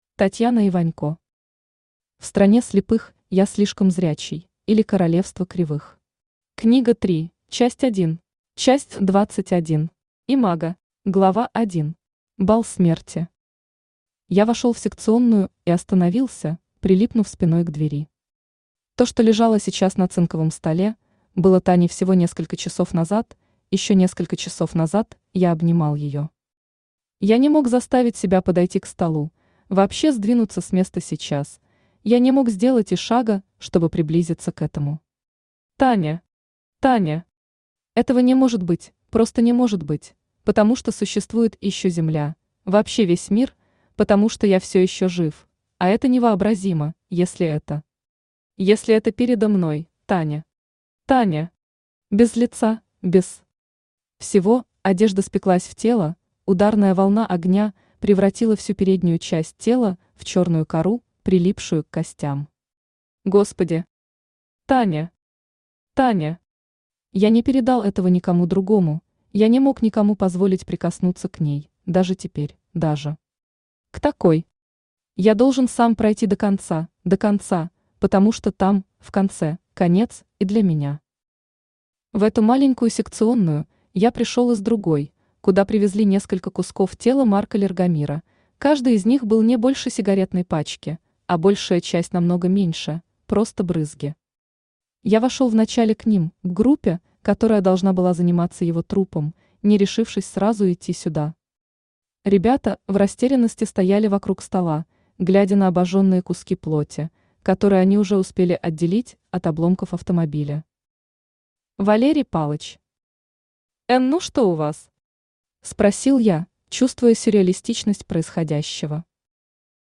Aудиокнига В стране слепых я слишком зрячий, или Королевство кривых. Книга 3, часть 1 Автор Татьяна Вячеславовна Иванько Читает аудиокнигу Авточтец ЛитРес.